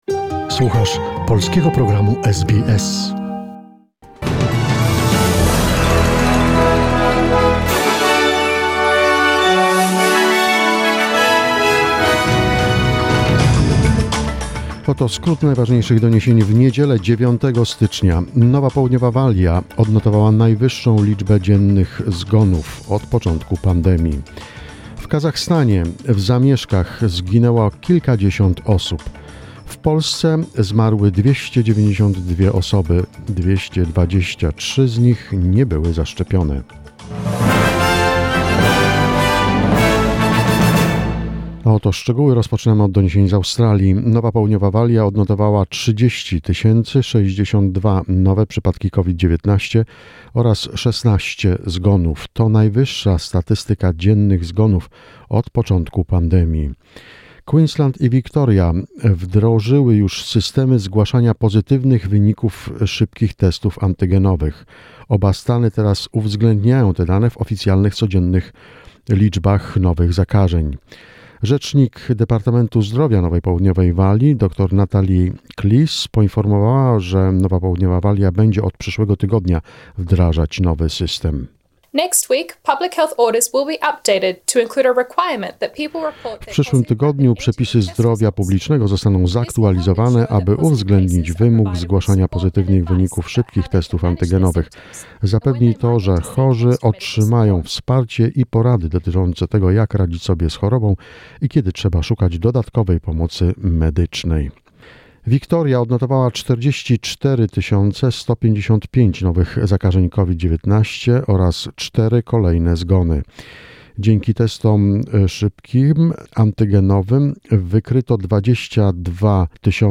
SBS News in Polish, 9 January 2021